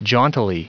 Prononciation du mot jauntily en anglais (fichier audio)
Prononciation du mot : jauntily